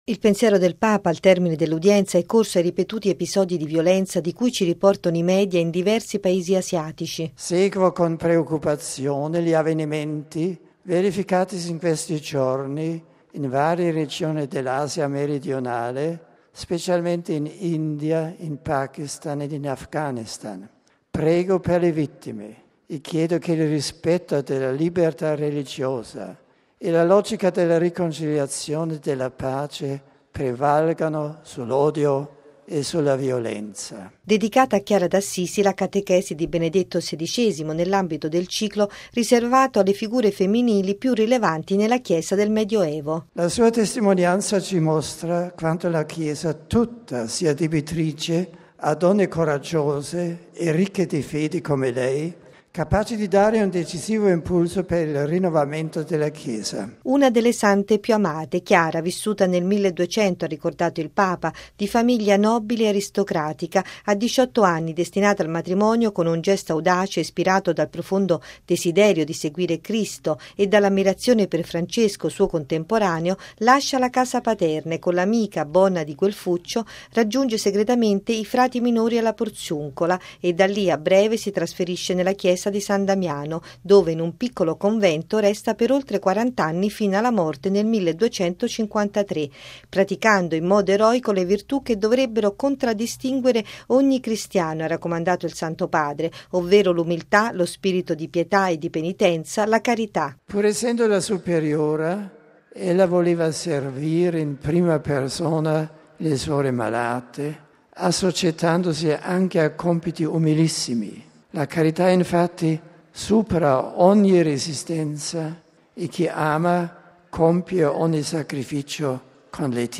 L’appello stamane, nell’aula Paolo VI, dopo la catechesi dedicata alla figura di Santa Chiara d’Assisi.